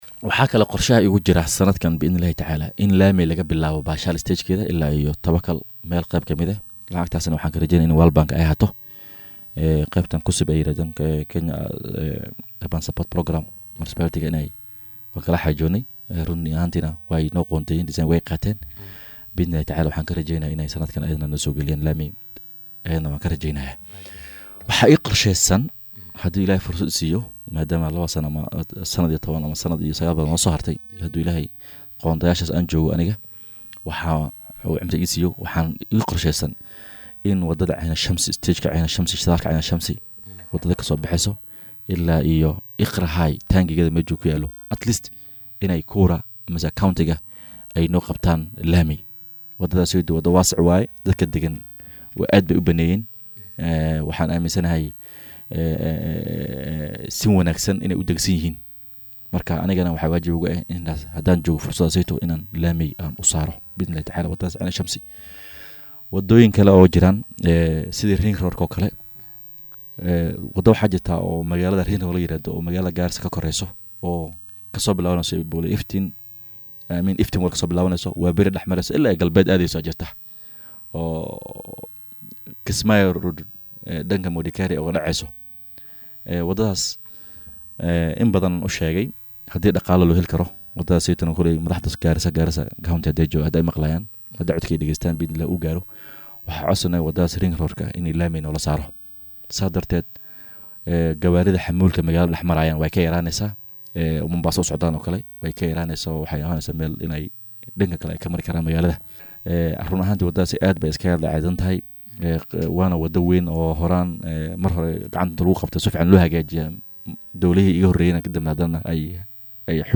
Wakiilka laga soo doortay waaxda Waaberi ee Magaalada Garissa Abubakar Xaaji Sugow oo saaka marti inoogu ahaa Barnaamijka Hoggaanka Star ayaa ka warbixiyay waxyaabo badan oo ku saabsan Hormarka Waaberi.